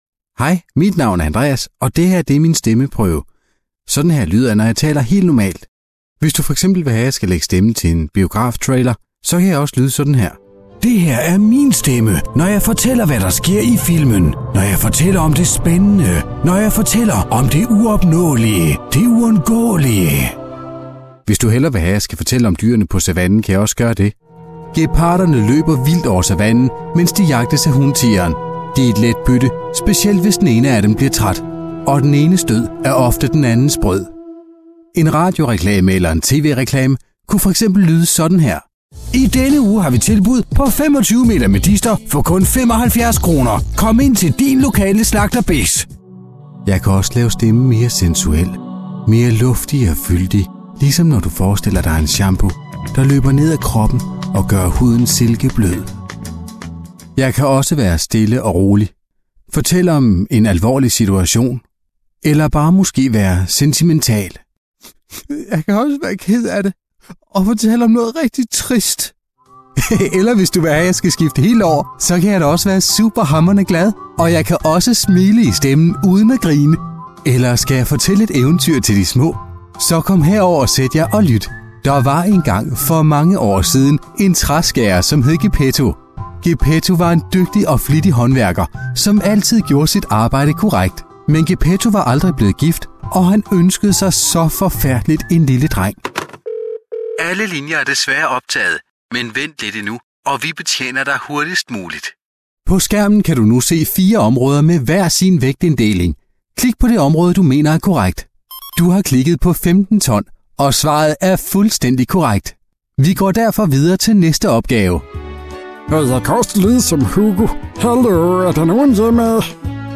Danish voice over from a leading voice over artist - Native from Denmark
Sprechprobe: Sonstiges (Muttersprache):